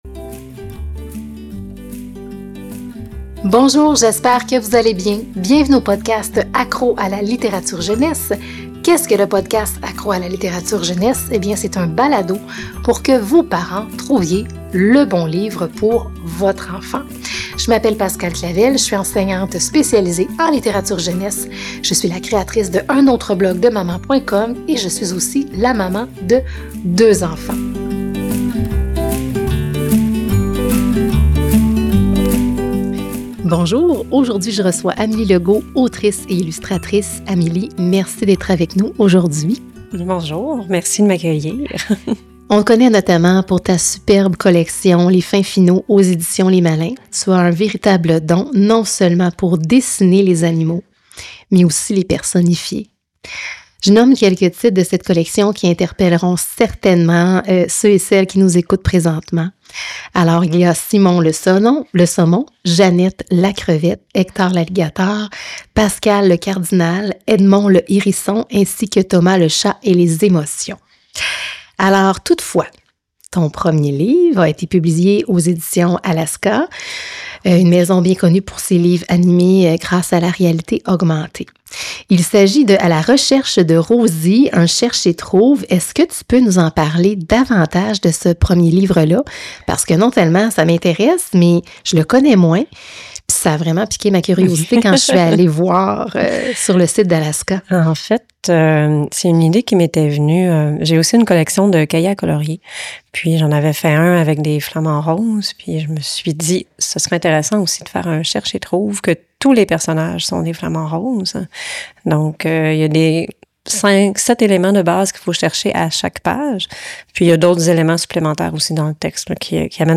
Épisode 29 : entrevue